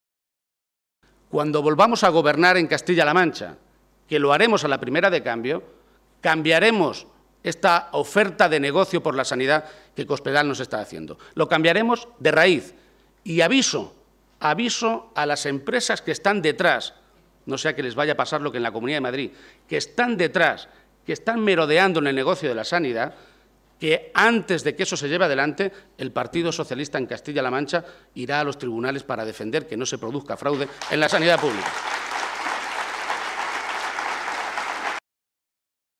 Page se pronunciaba de esta manera durante los Diálogos Ganarse el Futuro organizados a nivel nacional por el PSOE y que este domingo tenían a la ciudad de Albacete como sede para discutir sobre el modelo de Sanidad y servicios sociales.